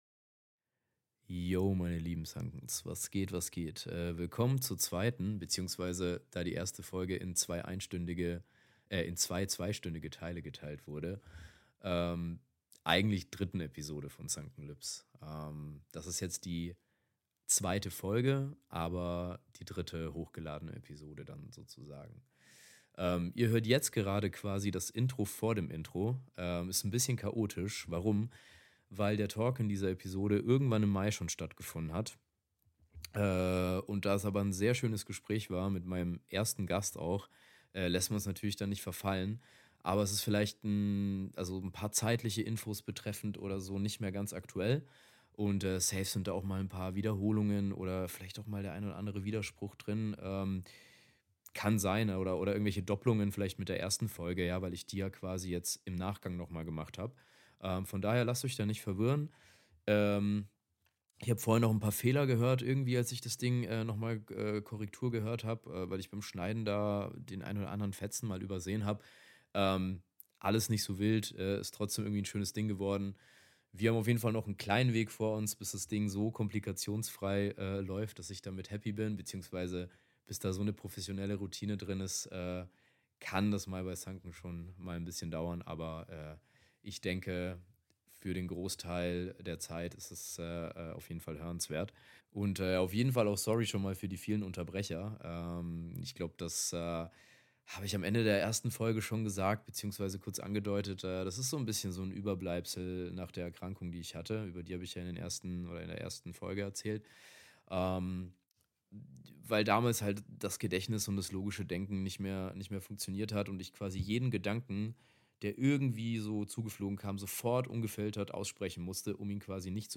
Bei meinem ersten Gast & mir war genau das der Fall.